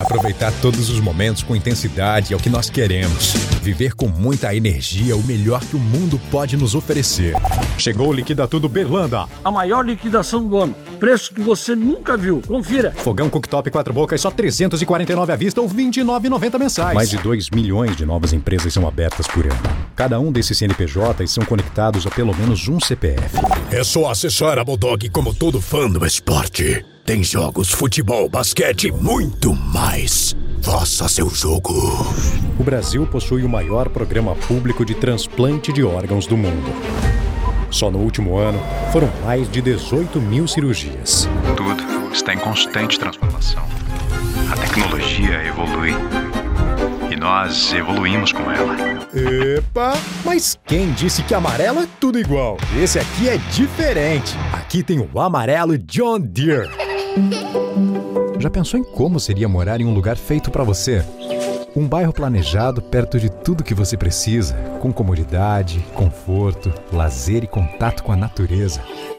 Commercial Demo
BassDeepLowVery Low
FriendlyWarmConversationalDarkCharming